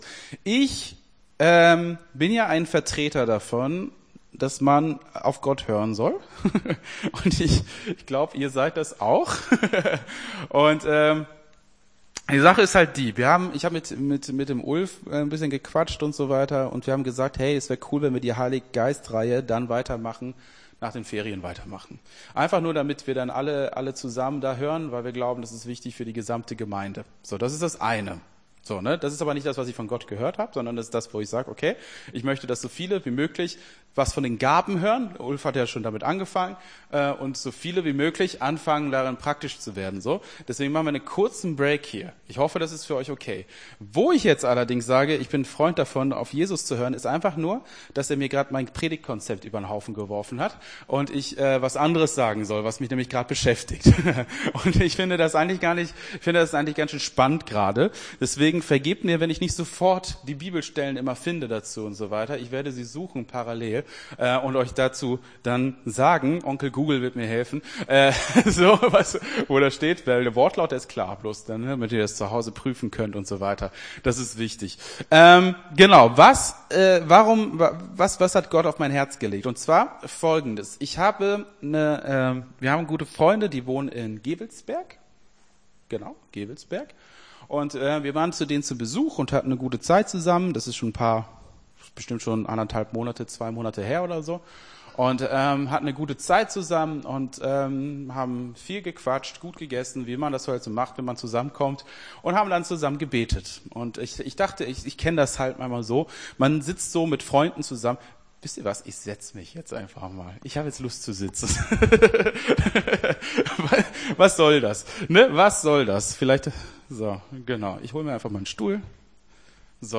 Gottesdienst 02.07.23 - FCG Hagen